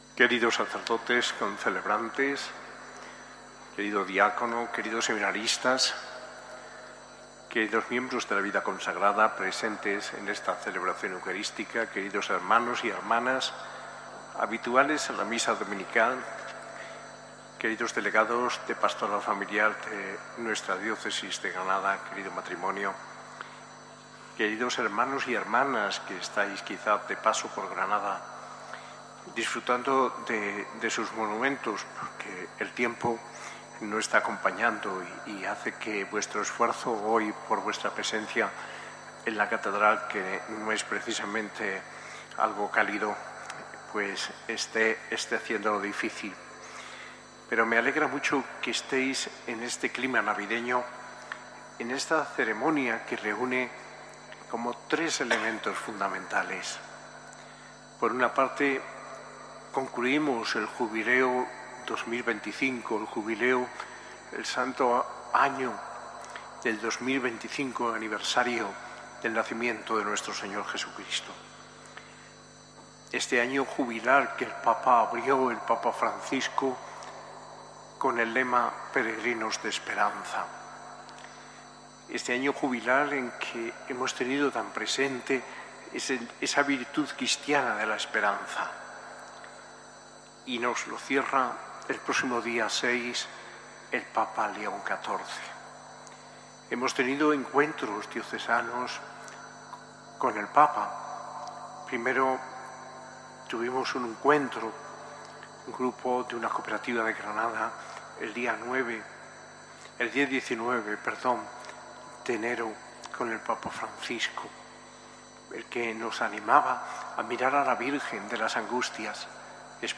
Homilía de Mons. José María Gil Tamayo, arzobispo de Granada, en la Eucaristía de clausura del Año jubilar de la esperanza, día de los Santos Inocentes y Jornada mundial de las familias, celebrada en la Catedral el 28 de diciembre de 2025.